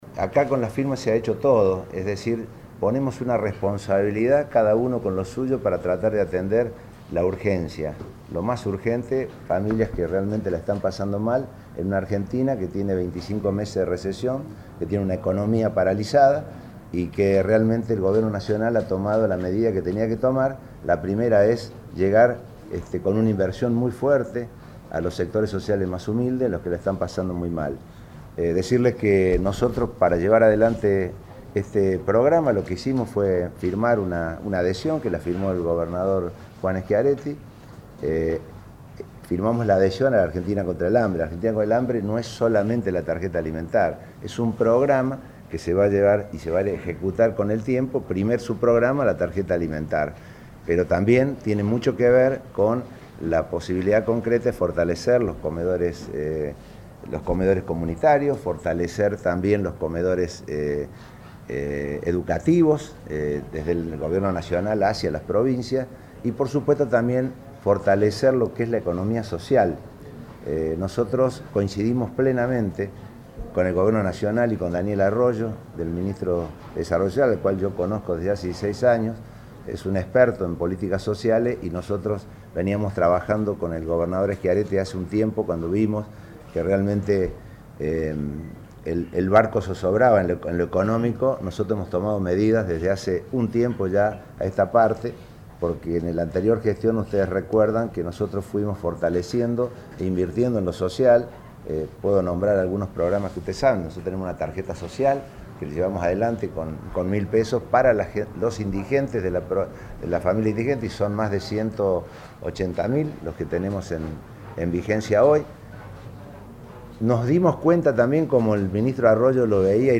El ministro de Desarrollo Social Juan Carlos Masei esto decía sobre el plan nacional de las tarjetas Alimentar.